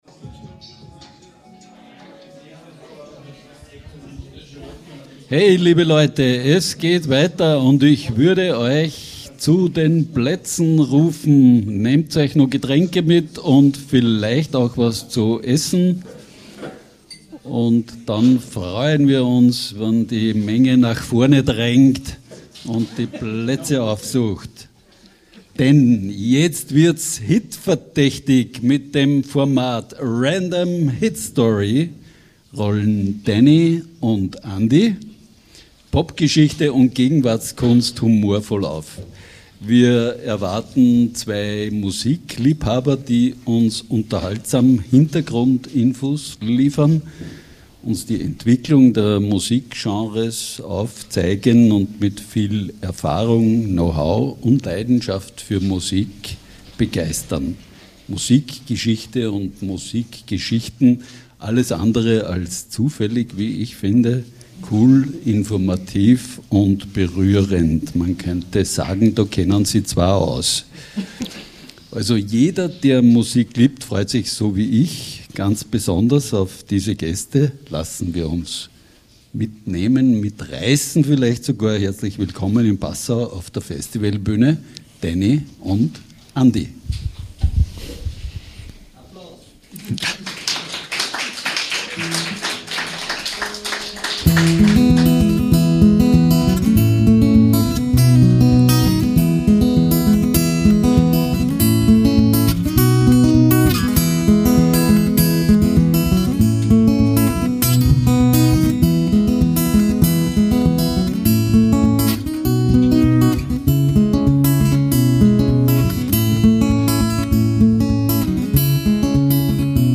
In dieser Live-Episode vom Passau–Podcast–Festival 2025 geht es um Live-Erlebnisse. Die meisten Menschen bewerten Konzerte nach Setlist und Show.